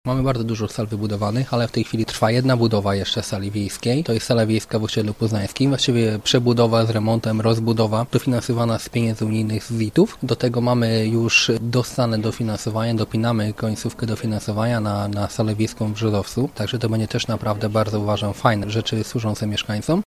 Obecnie rozbudowywana jest sala przy os. Poznańskim, niedługo miejsca spotkań doczeka się także Brzozowiec – mówi Paweł Tymszan, wójt gminy Deszczno: